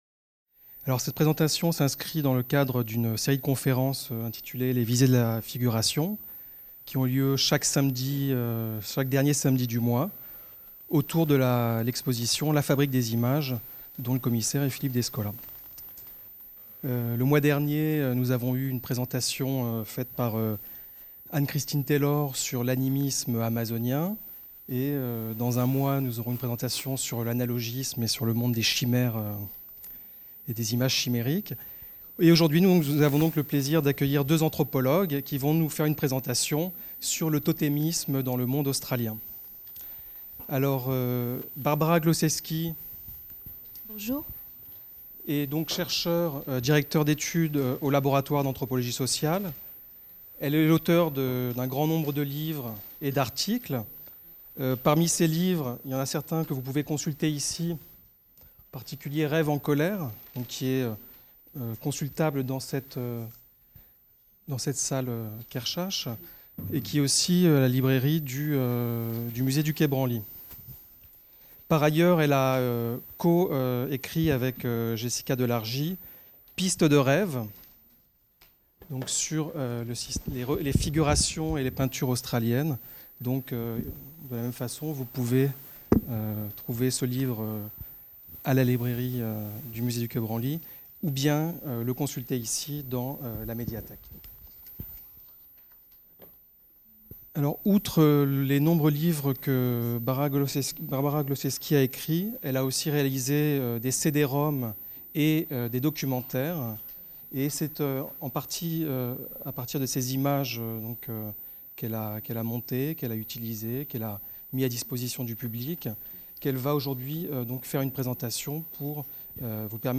Conférence enregistrée au Salon de lecture Jacques Kerchache le 24 avril 2010